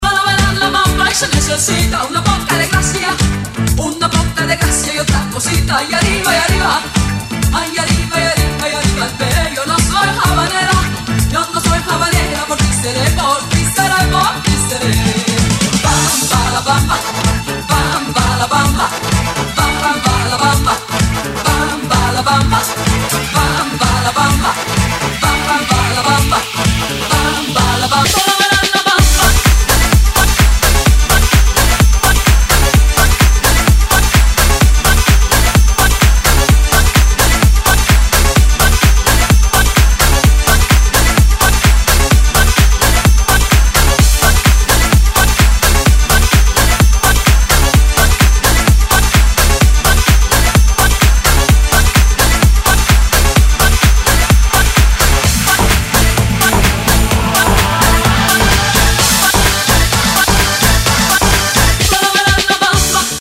• Качество: 128, Stereo
латинские
electro